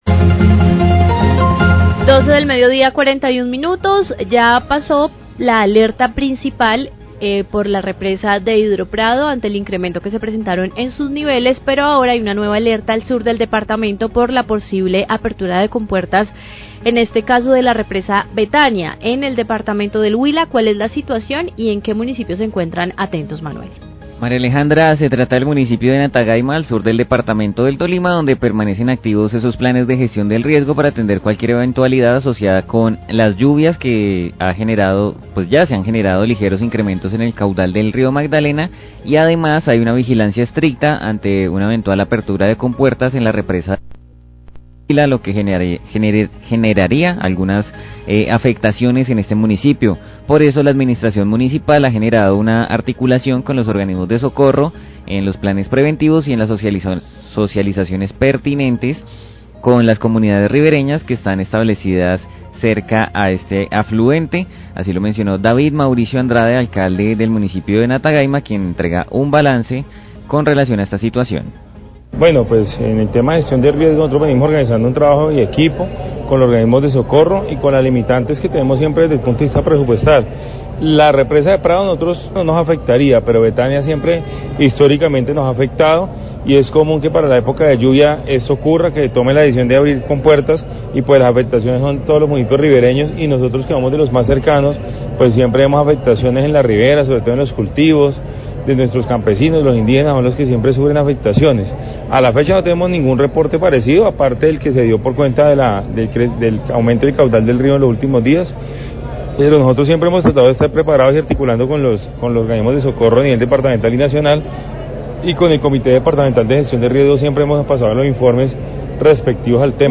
Radio
Luego finalizar la alerta roja por la represa de Prado, ahora se presenta una nueva emergencia por la posible apertura de compuertas en la central hidroeléctrica de Betania en el Huila por alto nivel del embalse. Habla el alcalde de Natagaima, David M. Andrade, sobre el trabajo mancomunado con entidades de socorro frente a esta situación.